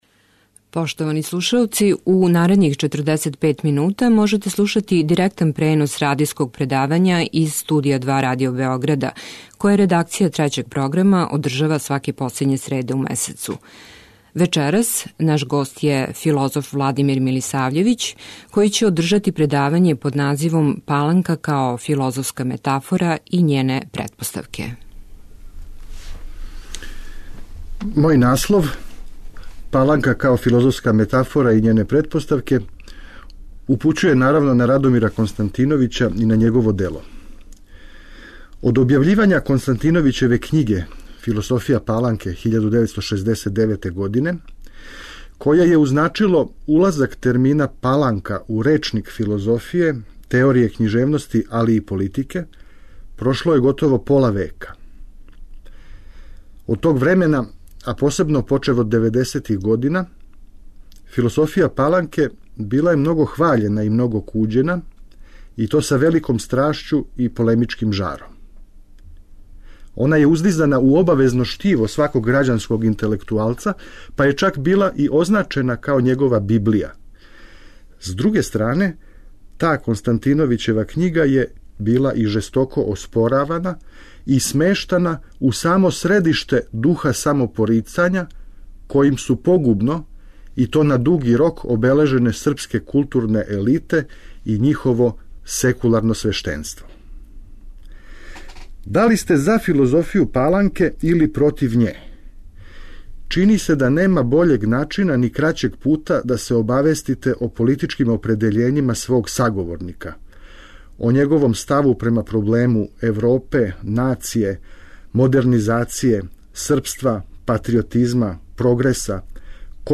Радијско предавање